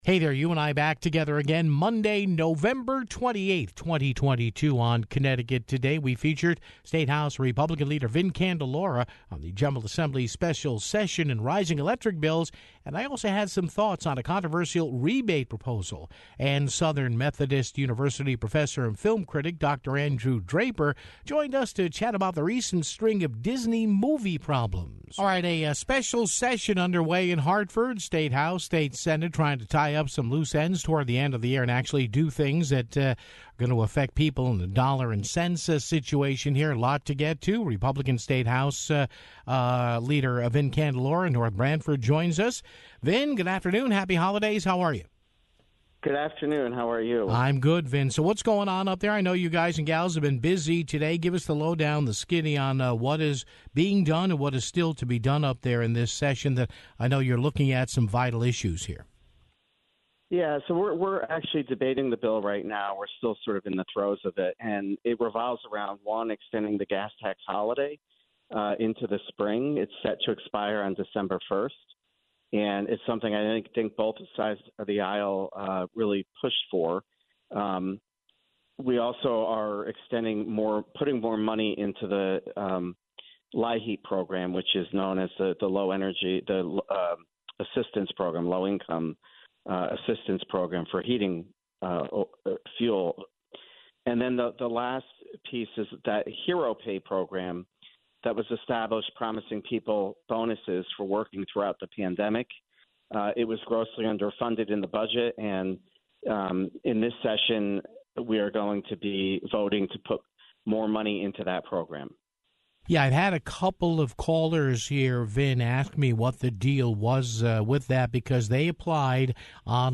featured State House GOP leader Vin Candelora on the General Assembly's special session and rising electric bills (0:25)